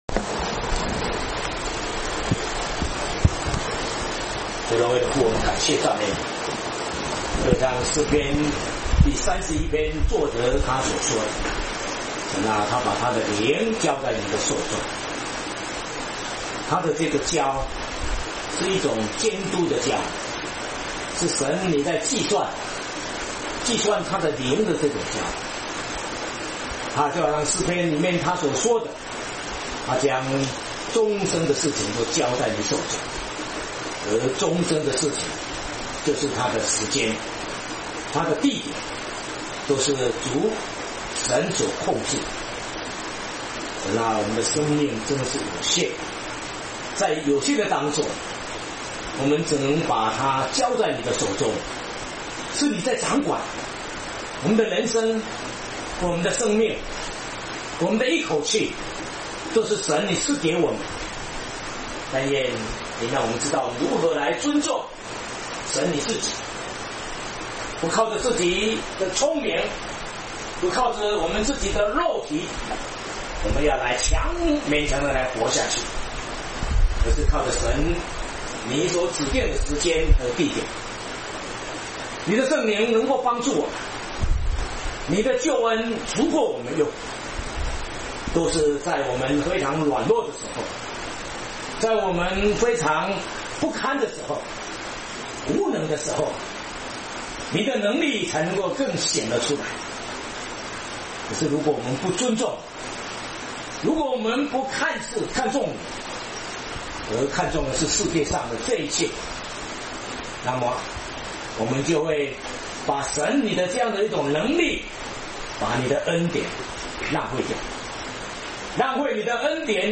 （詩篇 31）講解（詩 31:1-24） [雅威聖會 聖經真理研究院]
詩頌143、123、125首